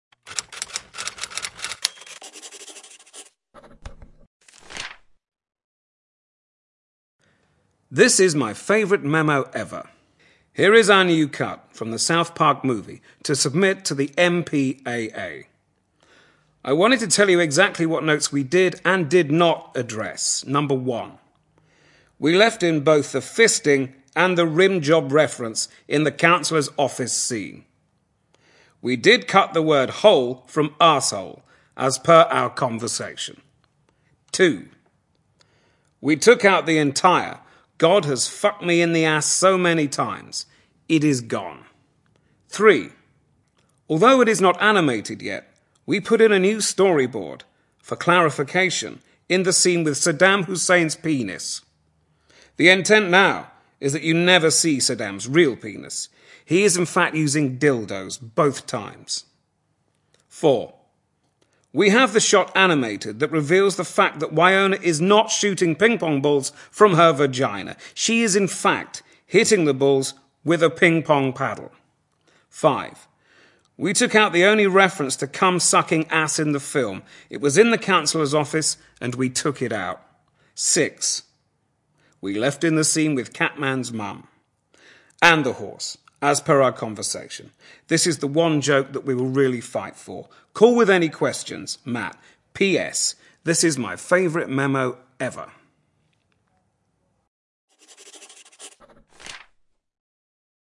见信如晤Letters Live 第20期:'马特贝里'读信:这是我最喜欢的备忘录 听力文件下载—在线英语听力室
在线英语听力室见信如晤Letters Live 第20期:'马特贝里'读信:这是我最喜欢的备忘录的听力文件下载,《见信如唔 Letters Live》是英国一档书信朗读节目，旨在向向书信艺术致敬，邀请音乐、影视、文艺界的名人，如卷福、抖森等，现场朗读近一个世纪以来令人难忘的书信。